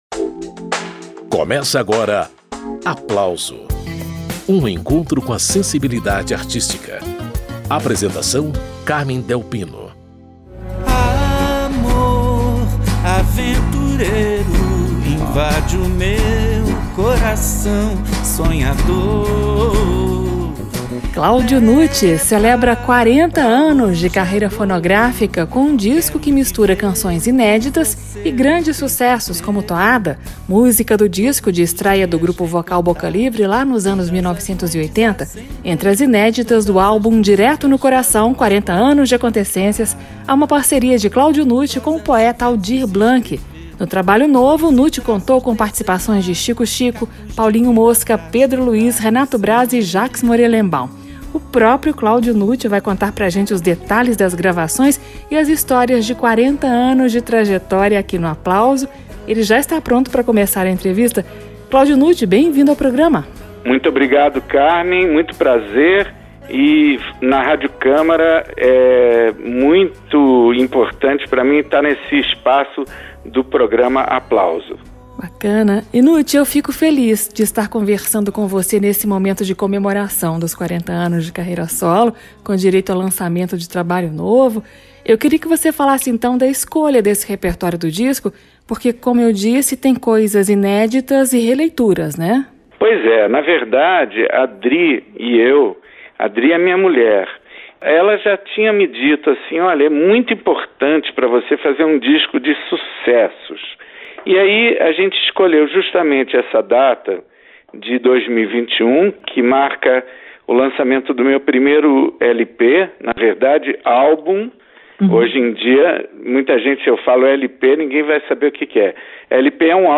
Na entrevista